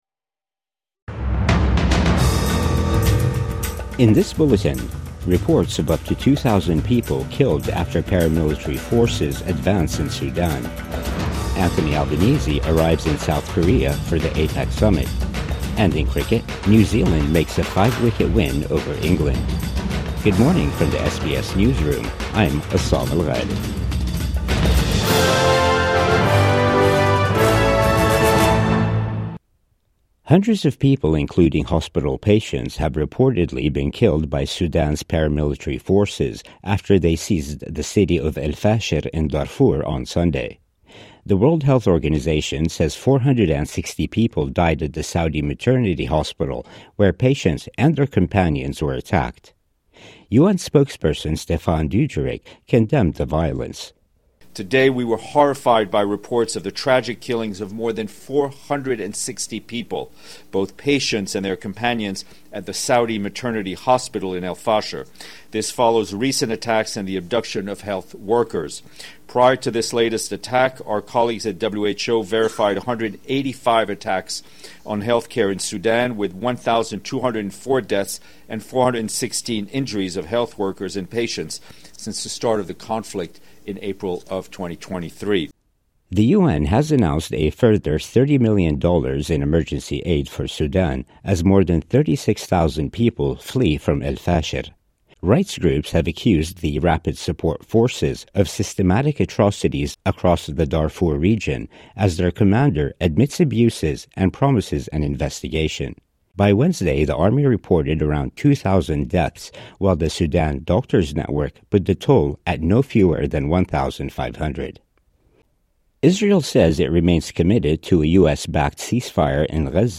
Up to 2,000 people killed after paramilitary forces advance in Sudan | Morning News Bulletin 30 October 2025